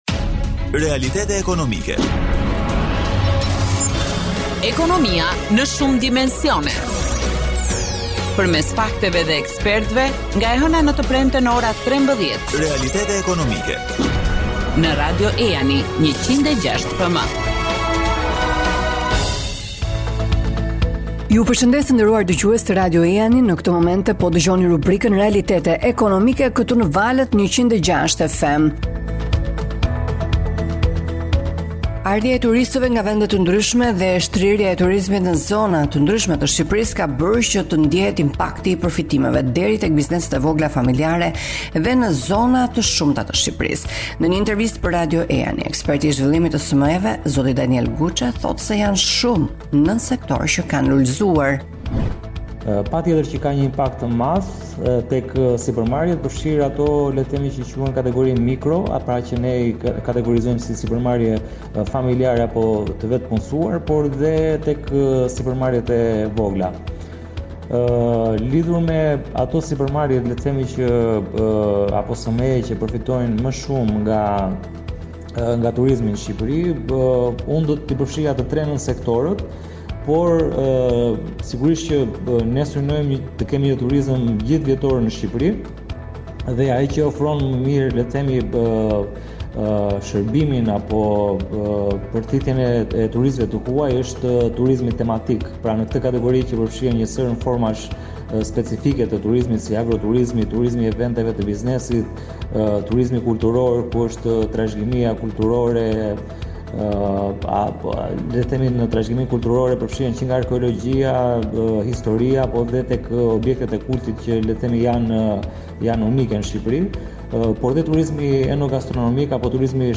Eksperti